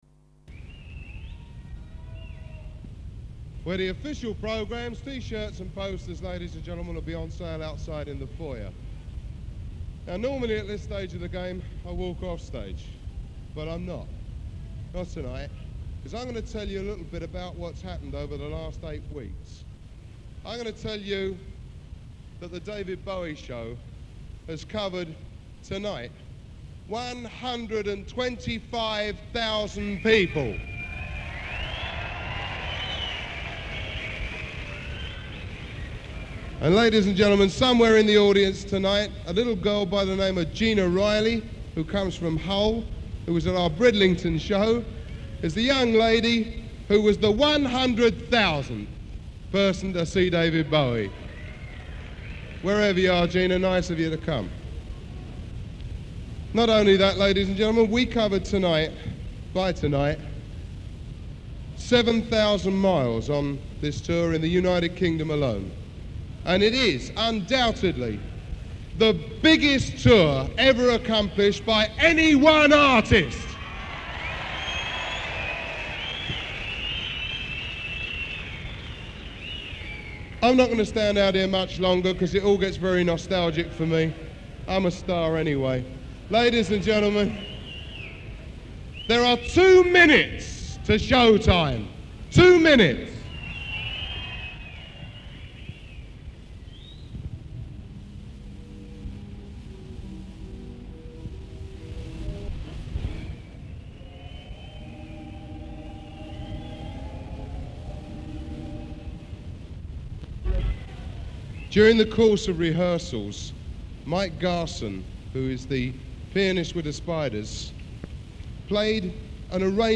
solo piano
Recorded live at the desk